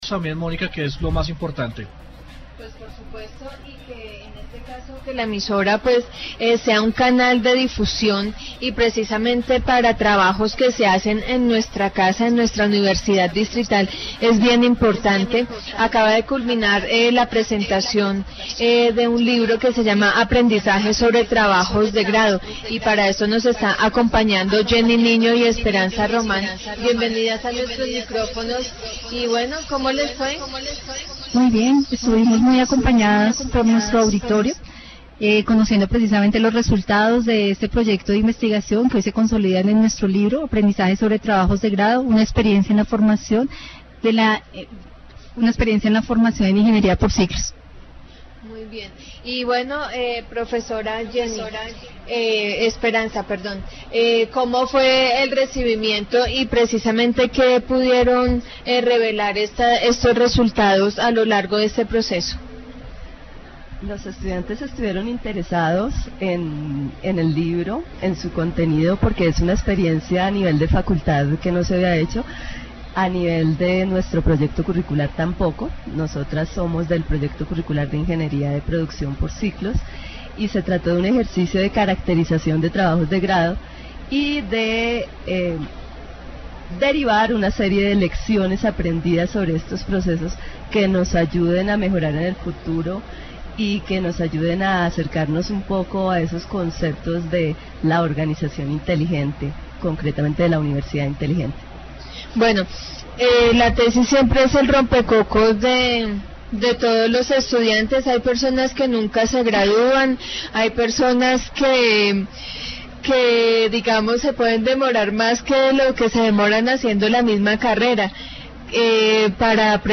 dc.subject.lembProgramas de radio
Radio report